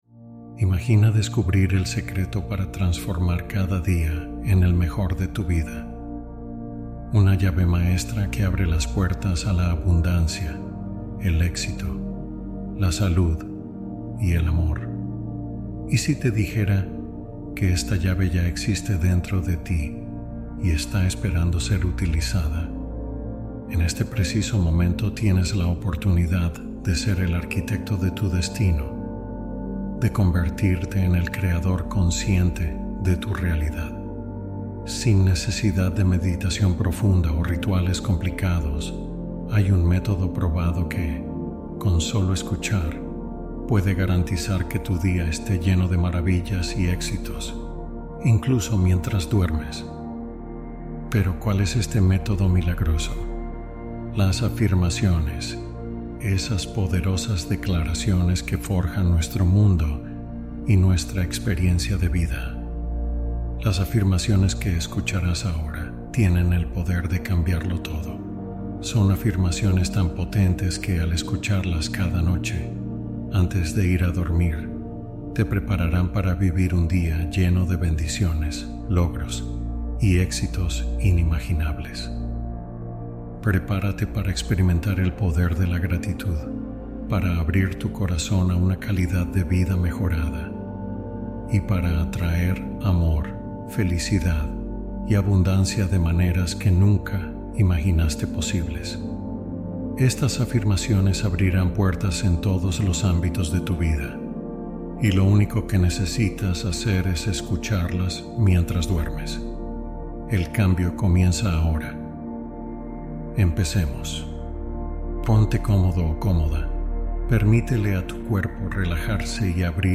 Reprograma Tu Subconsciente Mientras Duermes | Meditación Profunda